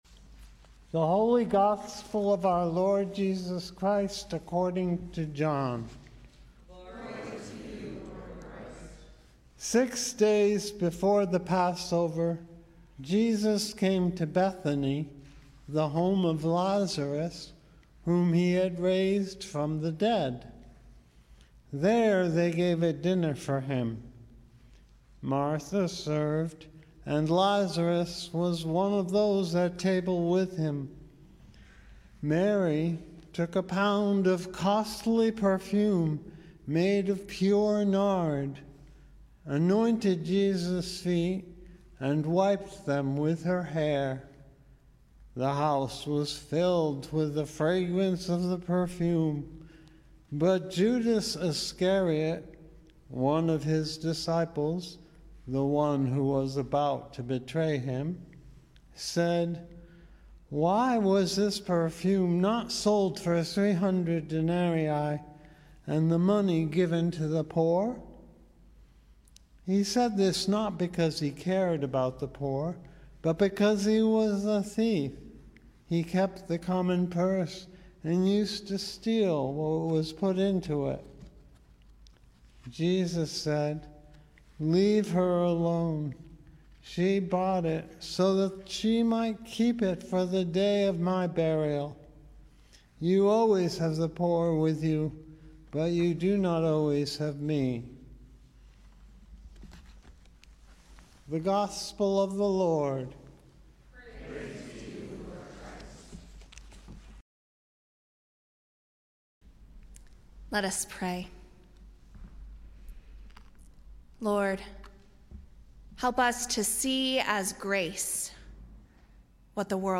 Gospel & Sermon, April 3, 2022 - St. Andrew's Episcopal Church